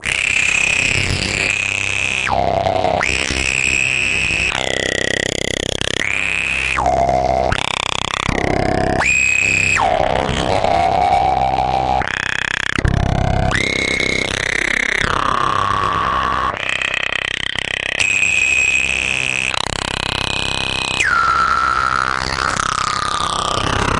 描述：工业电子循环，合成无人机，哔哔声气氛
Tag: 大气压 哔哔声 无人驾驶飞机 电子 工业 循环 合成